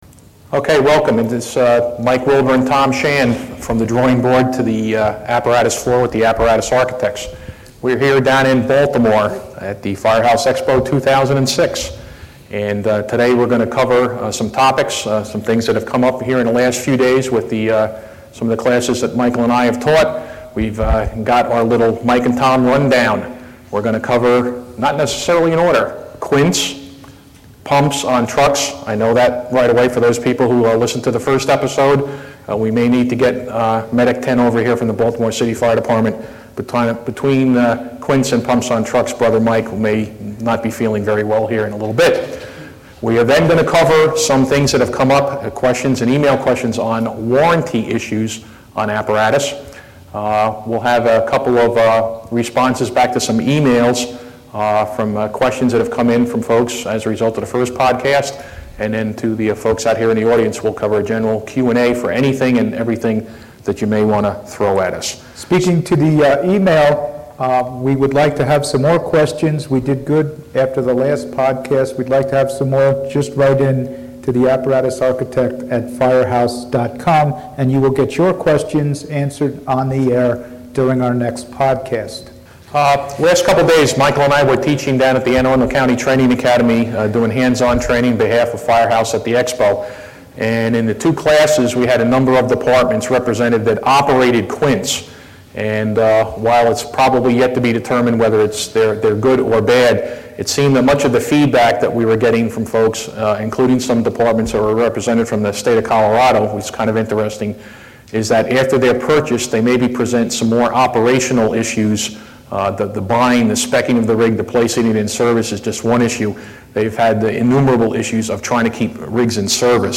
Live from Firehouse Expo